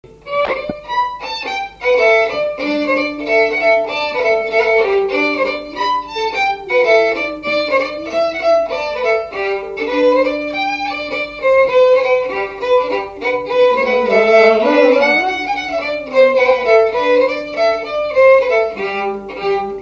Avant-deux
Résumé instrumental Usage d'après l'analyste gestuel : danse
Catégorie Pièce musicale inédite